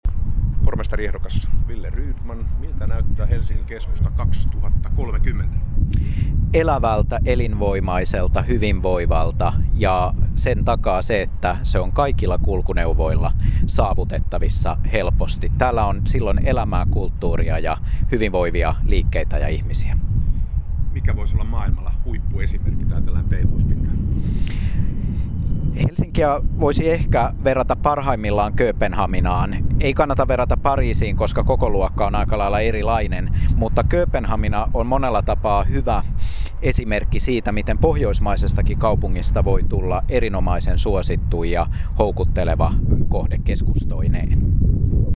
Pormestaripaneelissa Sofia Helsingissä kuultiin 26.3. ehdokkaita.